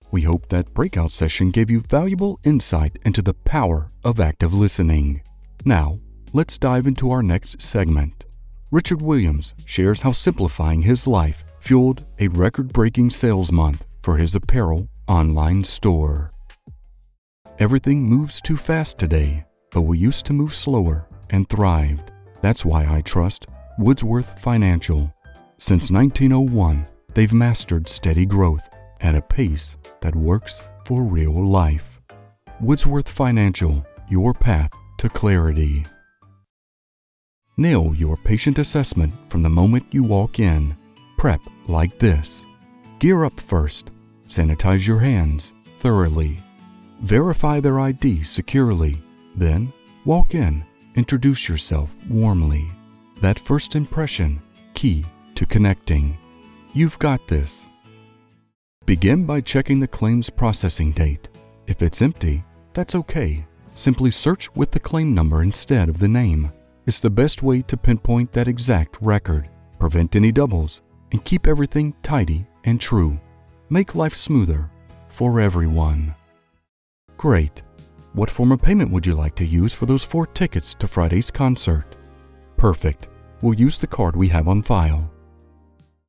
male voiceover artist with a rich, deep, and exceptionally smooth vocal tone.
Business Demo
General American, Southern
Voice Demo - Business.mp3